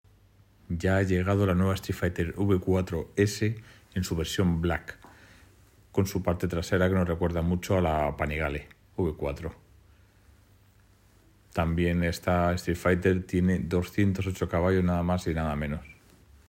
Ducati streetfighter V4S black edition sound effects free download